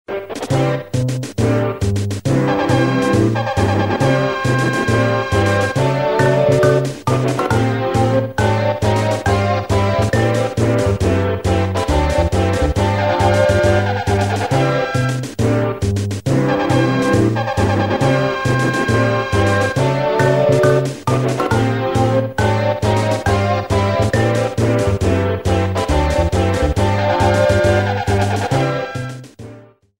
theme
Fair use music sample
30 seconds and fadeout You cannot overwrite this file.